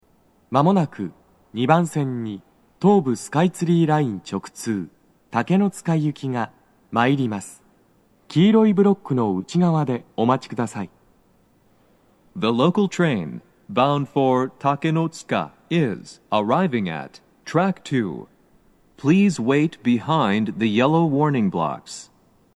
–Â“®‚ÍA‚â‚â’x‚ß‚Å‚·B